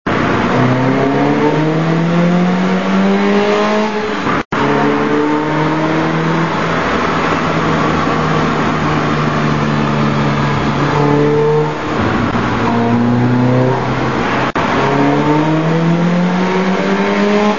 Opel Speedster with K&N in a tunnel (WMA 293Ko) or
(MP3 poor quality 69Ko)